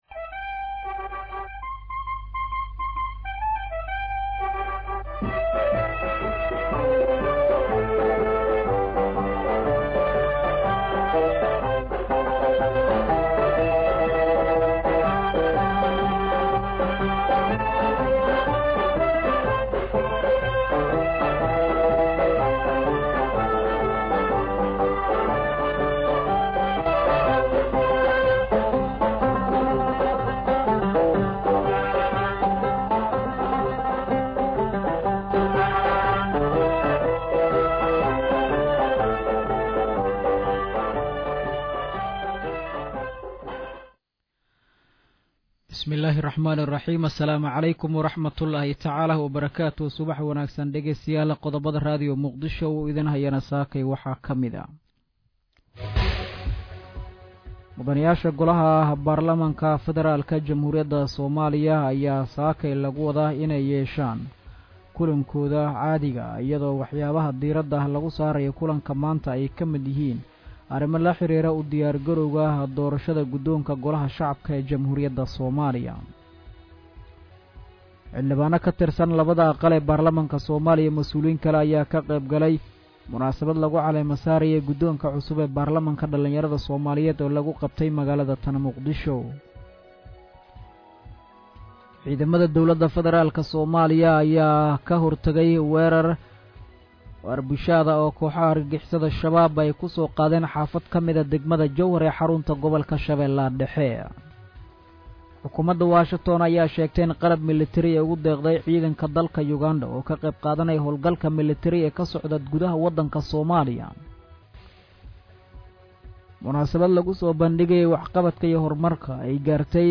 Dhageyso Warka Subax Ee Radio Muqdisho 4-1-2017